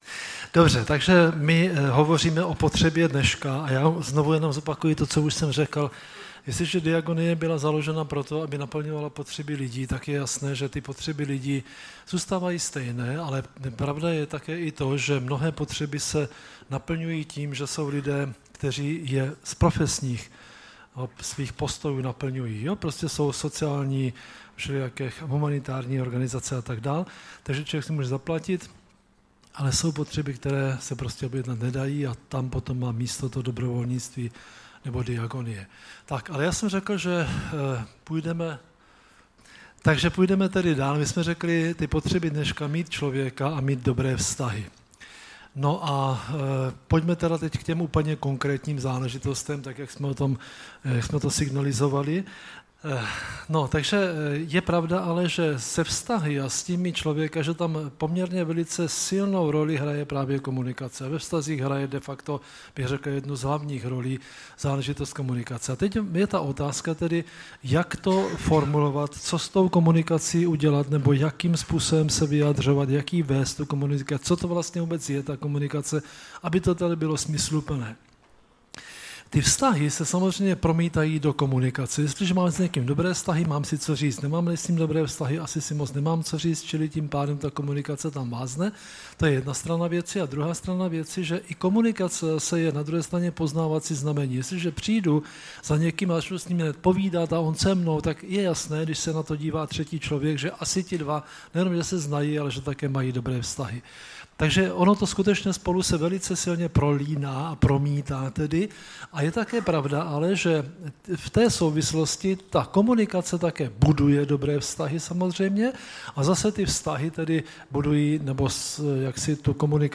Typ Služby: Přednáška